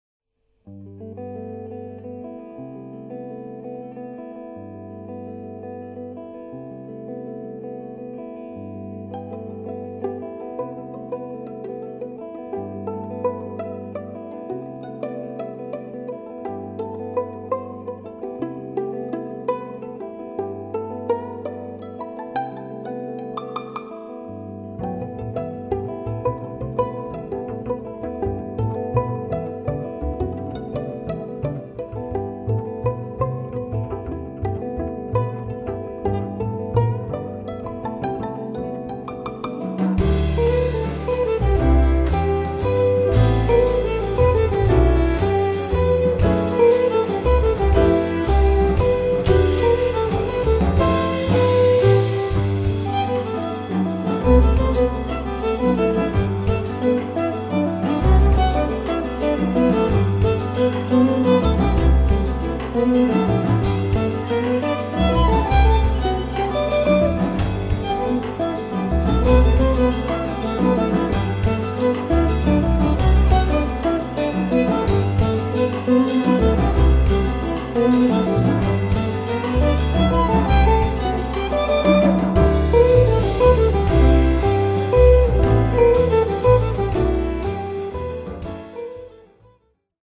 tenorski saksofon
violina
kitara
klavir, rhodes
kontrabas
bobni